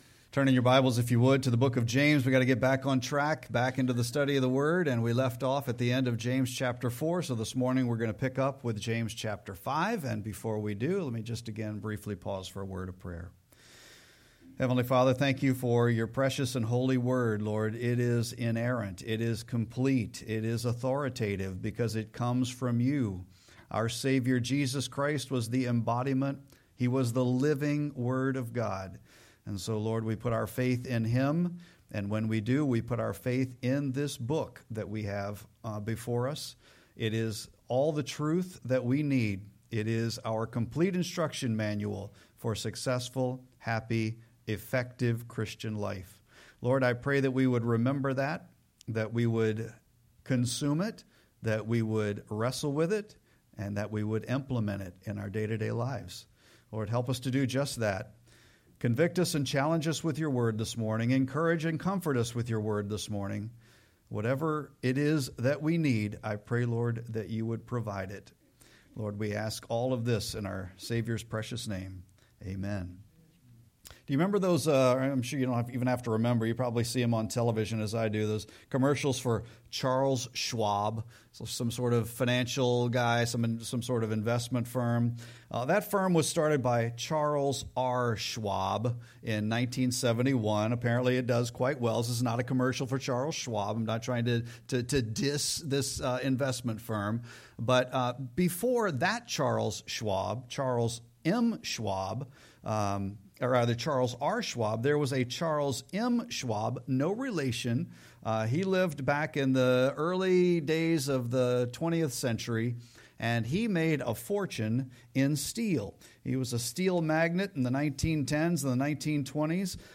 Sermon-9-14-25.mp3